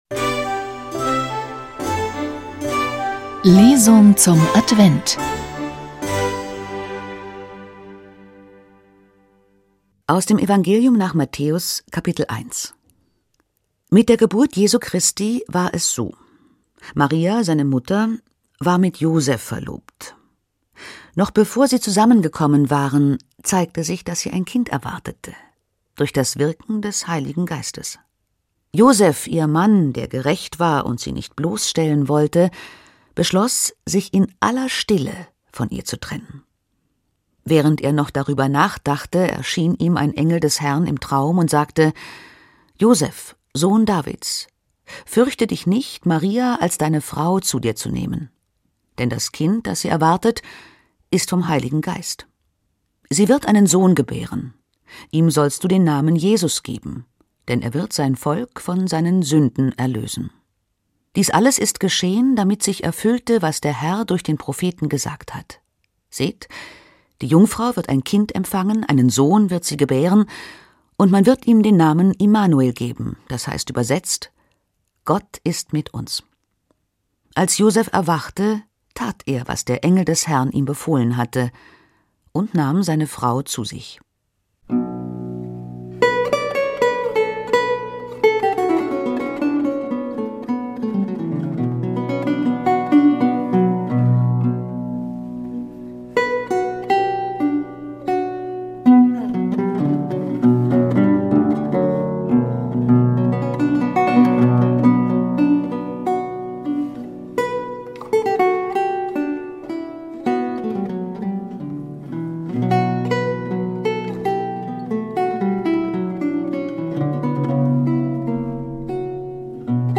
Lesung zum Advent